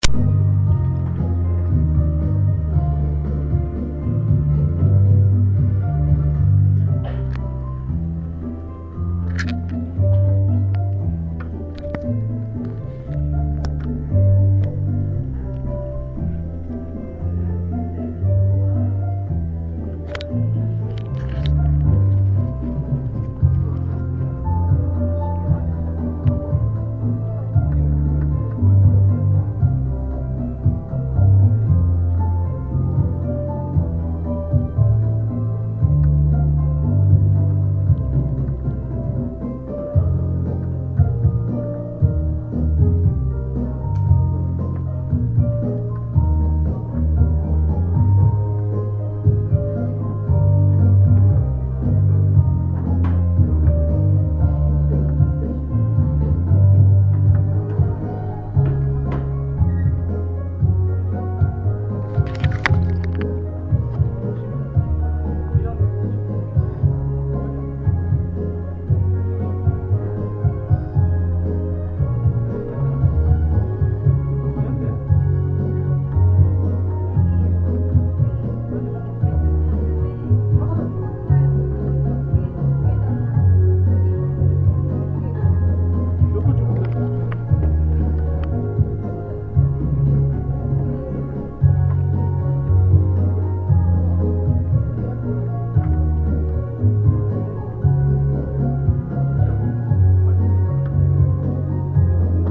곡이 약간 mid로 만든 듯한 느낌이 들었는데 힌트가 되련지요..
급 녹음한거라 음질이 조금 안좋습니다. 소리를 많이 높이시면 들립니다.